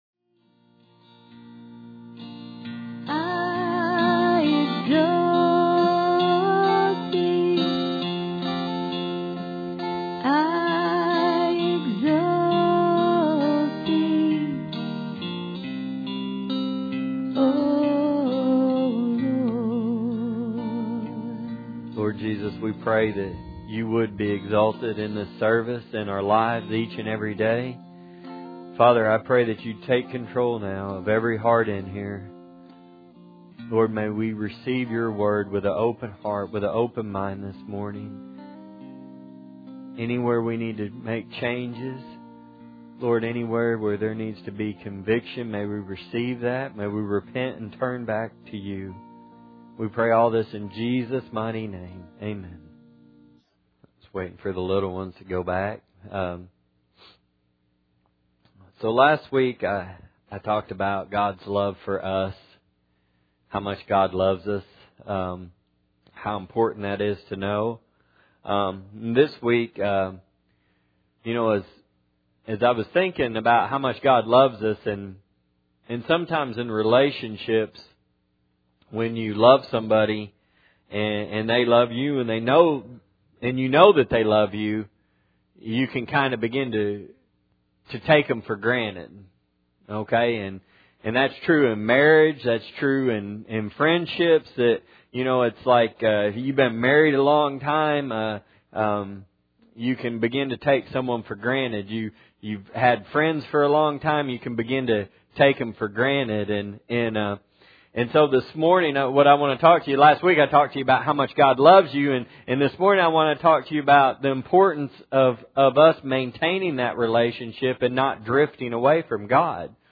Hebrews 2:1- Matthew 7 Service Type: Sunday Morning Bible Text